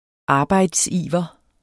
Udtale [ ˈɑːbɑjds- ]